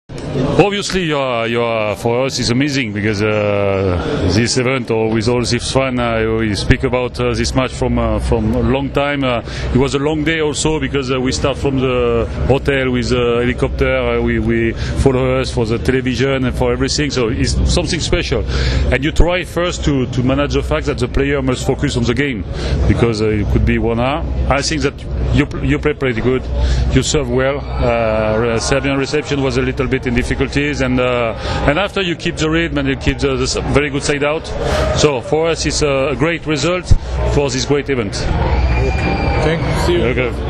IZJAVA FILIPA BLANA